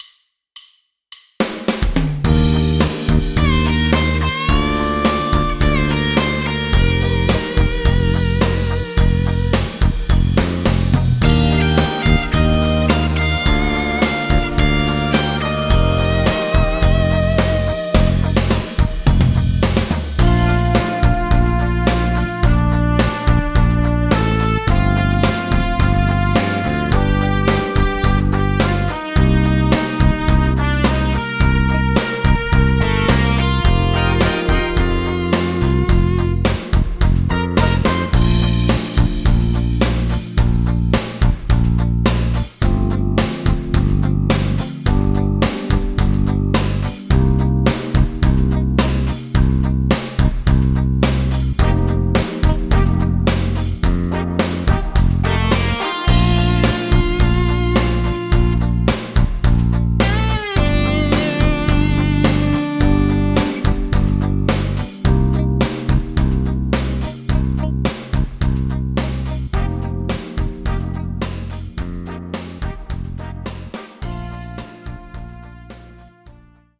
Karaoke, Instrumental